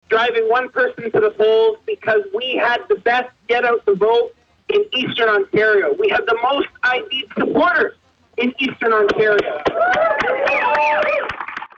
Piccini spoke to an enthusiastic audience of supporters following his win.